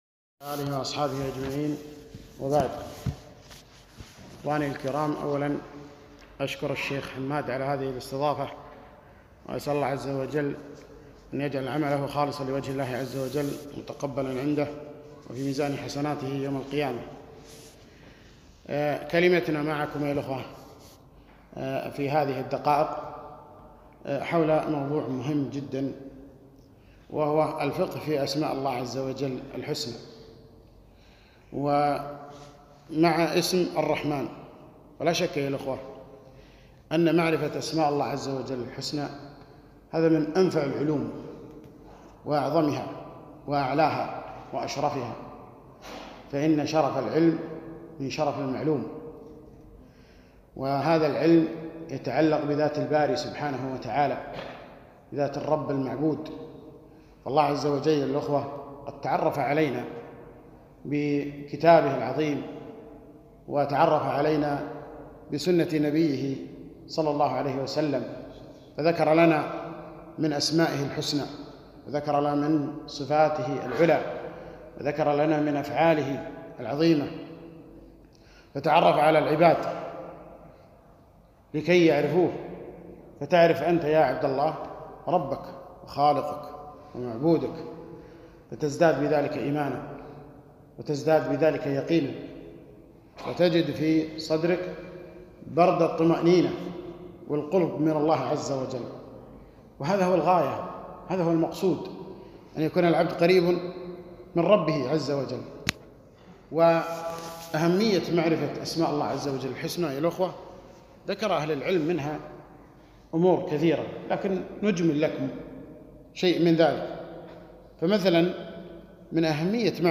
محاضرة - اسم الله (الرحمن)